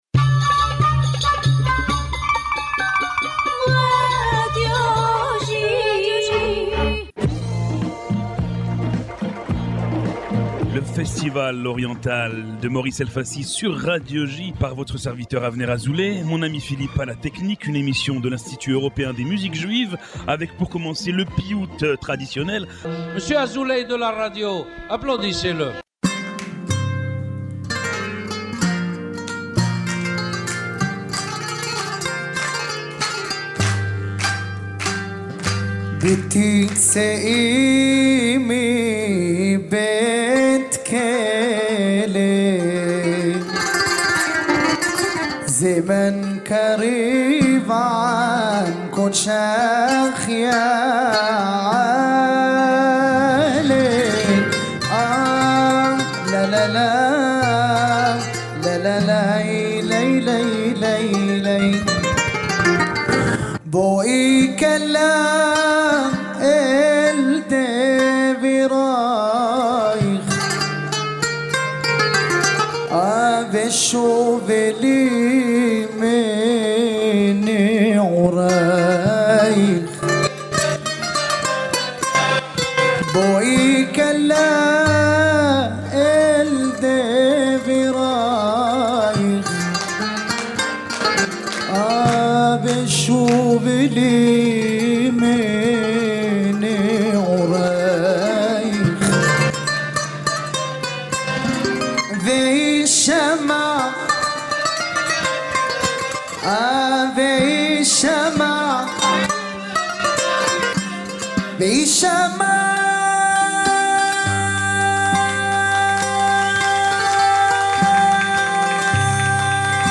Diffusé chaque lundi sur les ondes de Radio J (94.8 FM), Le festival oriental est une émission de l’Institut Européen des Musiques Juives entièrement dédiée à la musique orientale.